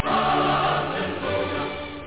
Amiga 8-bit Sampled Voice
1 channel
halleluhjah.mp3